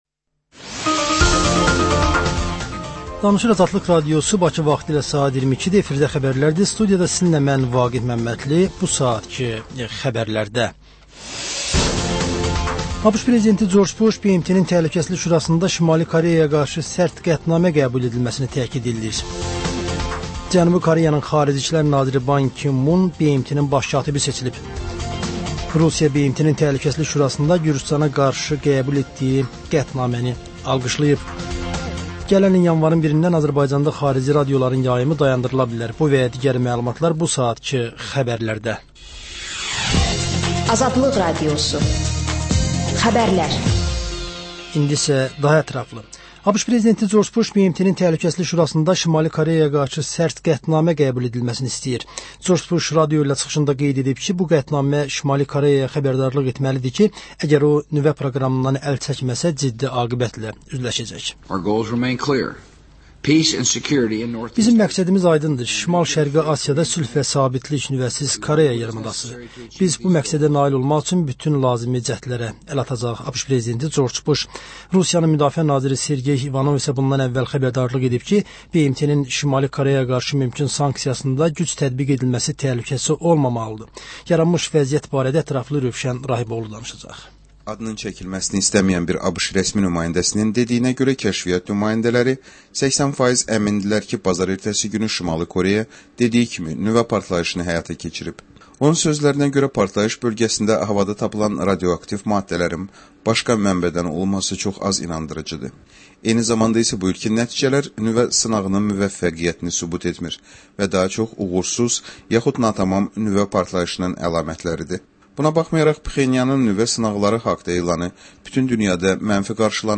Xəbərlər, reportajlar, müsahibələr. Və: Qafqaz Qovşağı: Azərbaycan, Gürcüstan və Ermənistandan reportajlar.